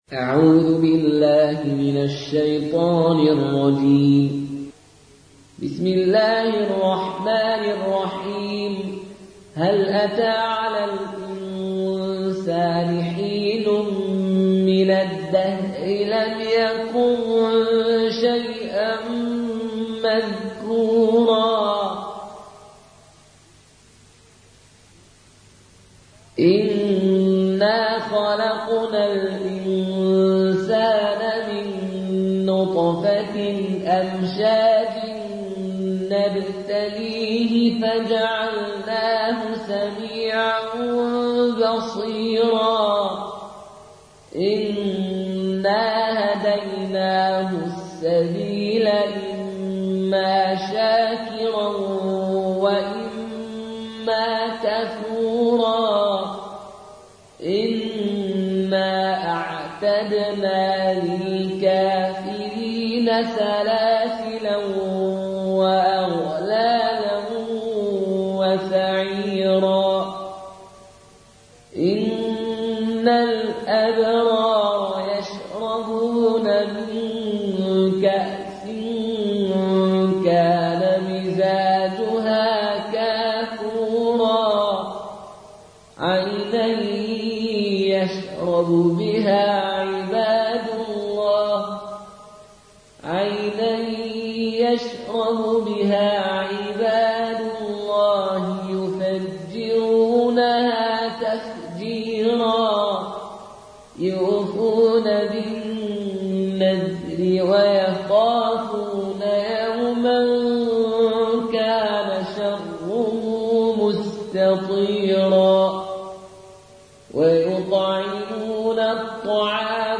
(روایت فالون)